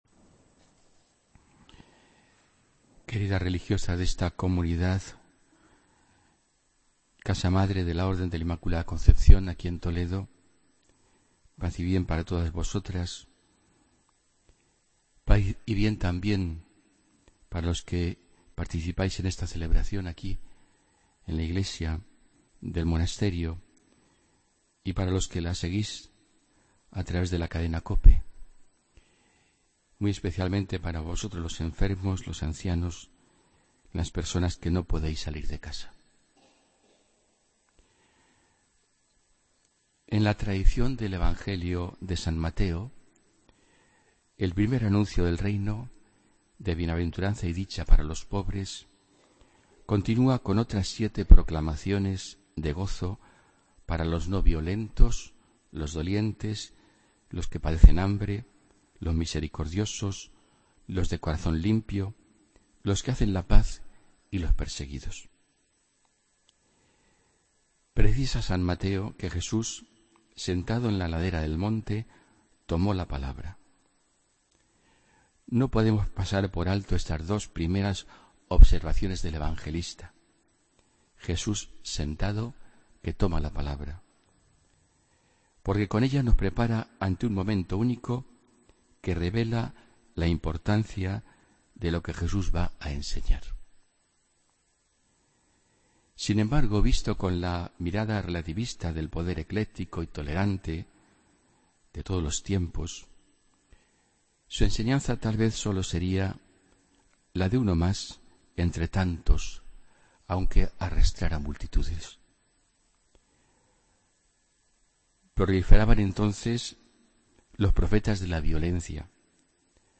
Homilía del domingo 29 de enero de 2017